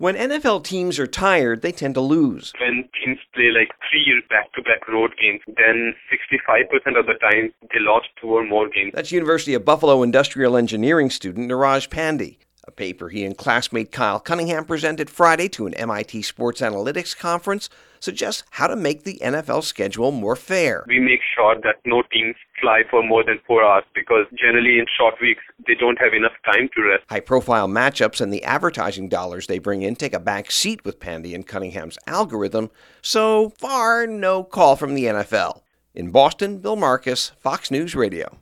HAS MORE FROM BOSTON WHERE THE PAPER WAS PRESENTED: